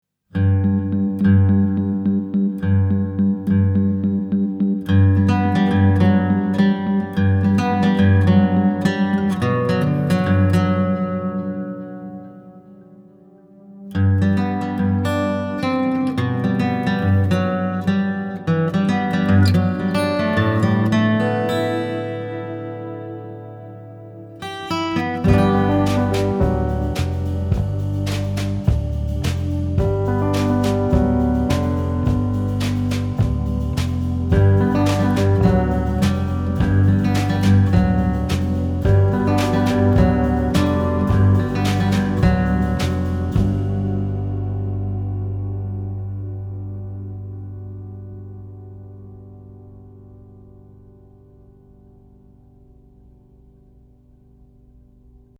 Recorded & Mixed at Mikemayer Music Studio